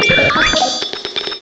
pokeemerald / sound / direct_sound_samples / cries / porygon_z.aif